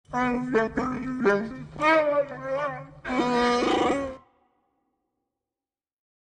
Звуки Чубакки
Чубакка говорит — вторая версия